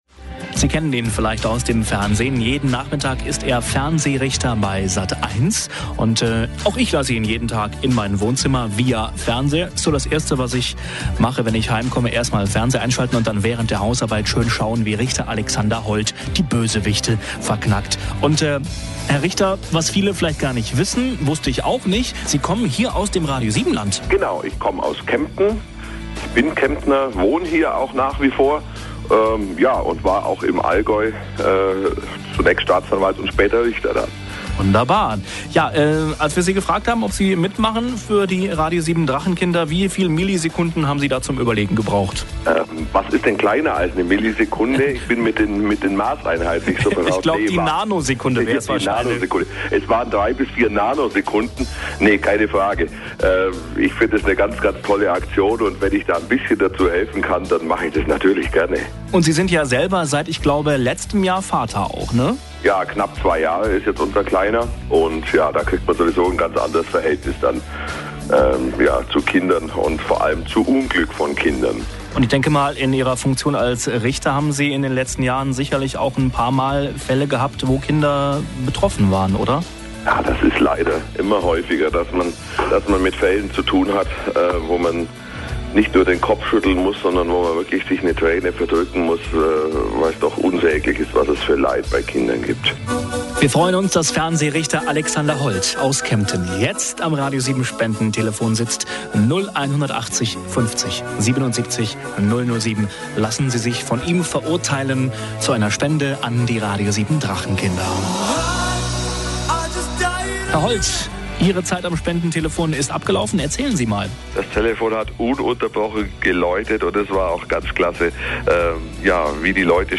Fernsehrichter Alexander Hold, gebürtig aus und wohnhaft in Kempten im schönen Allgäu, war einer der Promis am RADIO7-Spendentelefon.